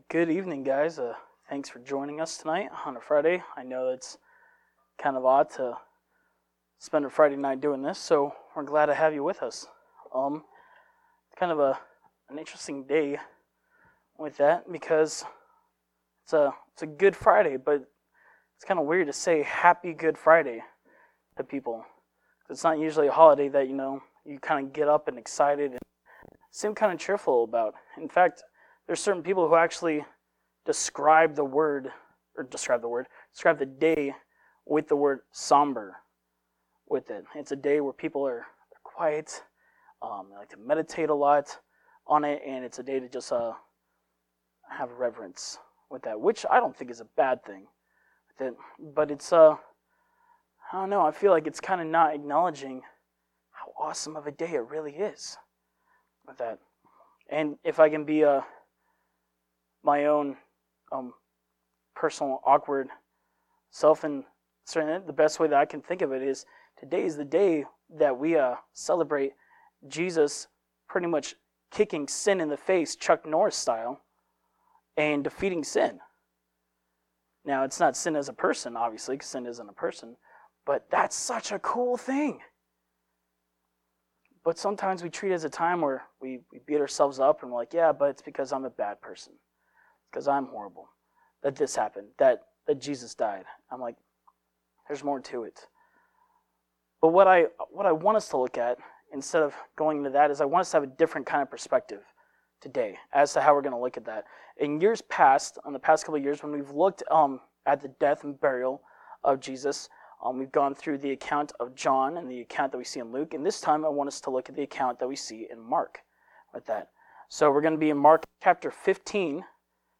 Good Friday Service 2020
Good-Friday-Service-2020.mp3